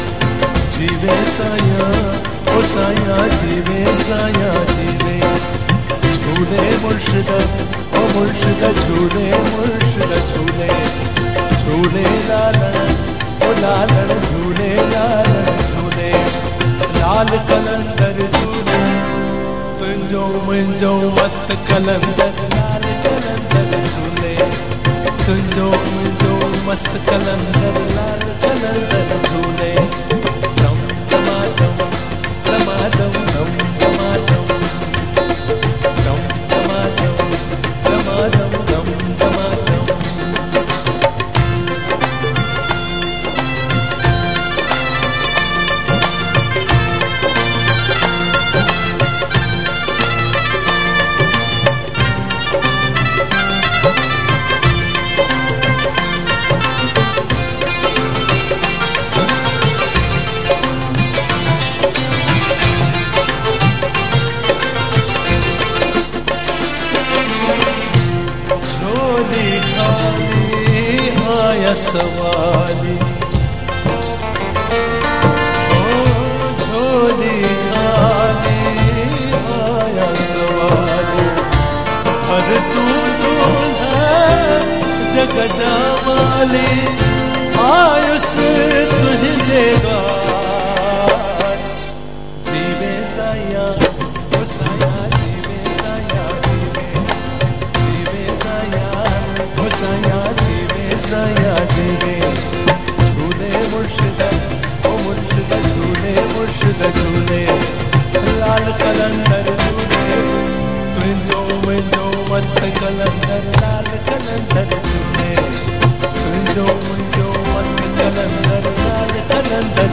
Format: LIVE
Live Performance